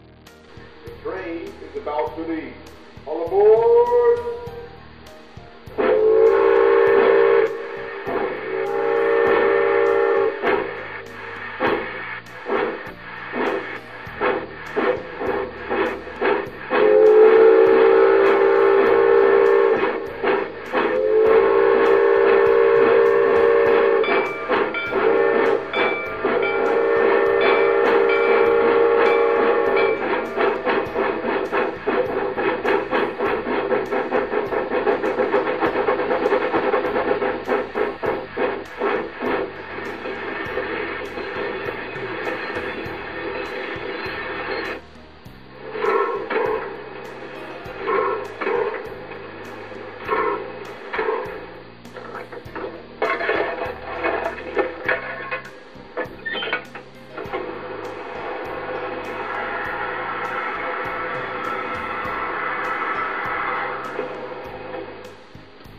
Der eMOTION XLS Sounddecoder gibt die authentischen Geräusche einer Lokomotive in hochwertiger digitaler Qualität wieder. Hierzu werden Soundaufnahmen direkt am Vorbild vorgenommen und dann im Soundlabor für die Elektronik abgeglichen.
Die Hintergrundmusik in den MP3-Demo Dateien ist nicht im XLS-Modul vorhanden!
Soundgeräusch